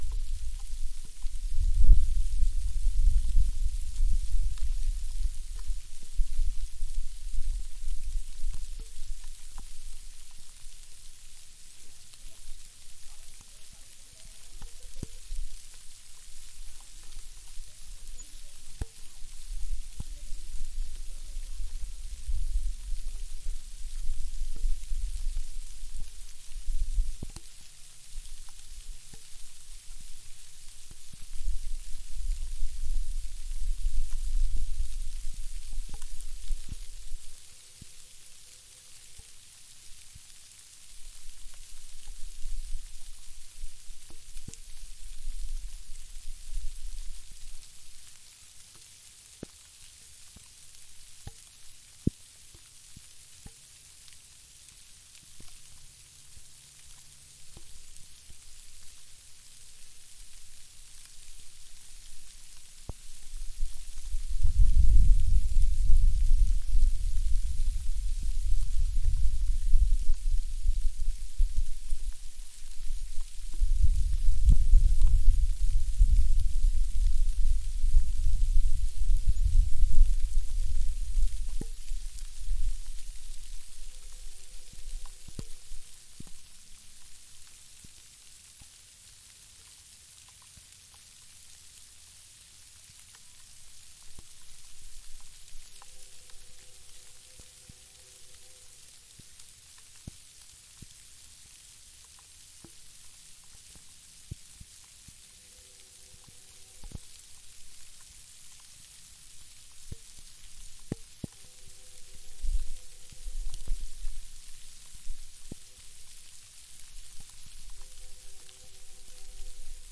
描述：Heavy rain shower with hail during a winter thunderstorm hitting against our window pane. Zoom H4n
标签： winter wind hail thunderstorm windowpane fieldrecording
声道立体声